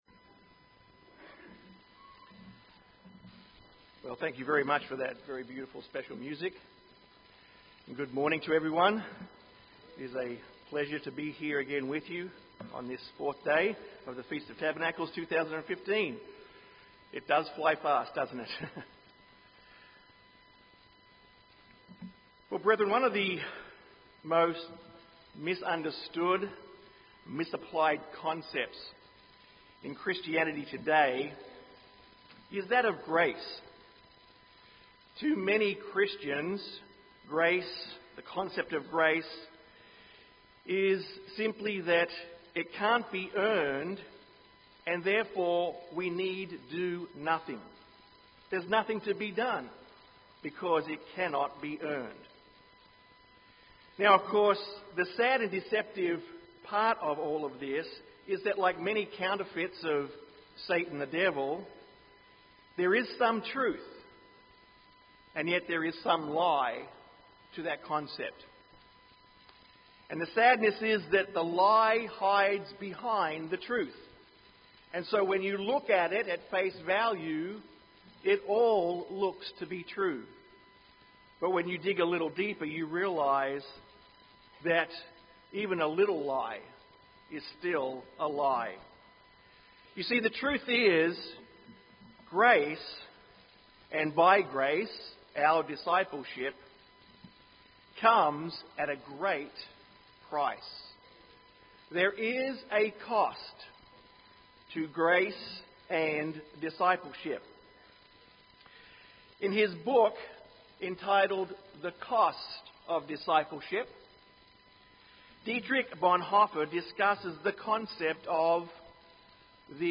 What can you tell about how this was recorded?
This sermon was given at the Galveston, Texas 2015 Feast site.